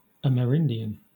Ääntäminen
Synonyymit Indian Ääntäminen Southern England RP : IPA : /ˌæməˈɹɪndɪən/ GA: IPA : /ˌæməɹˈɪndiən/ Lyhenteet amer.